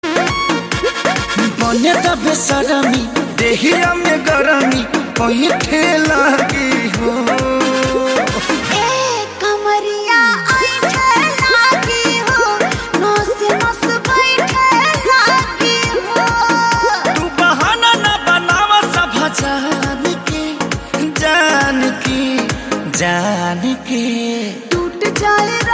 Romantic Bhojpuri hit